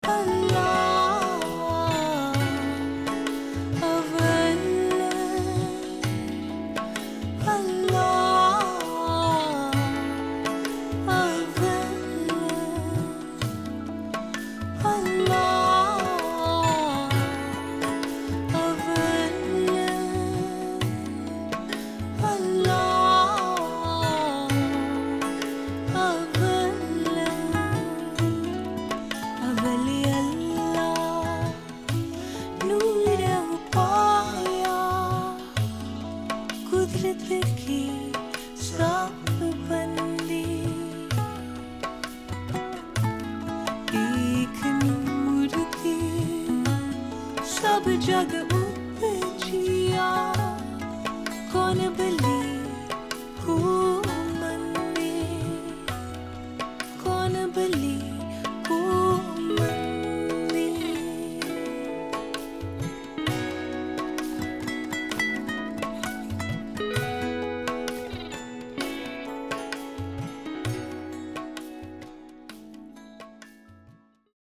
World music